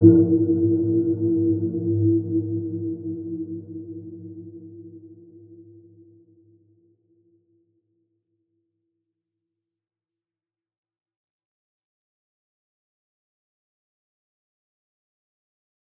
Dark-Soft-Impact-E4-mf.wav